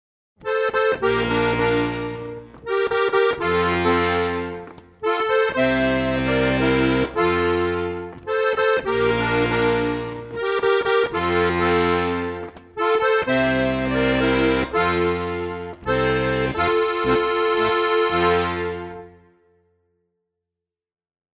• für 3-reihige Harmonika
• für 4-reihige Harmonika
• Schwierigkeitsgrad: leicht spielbar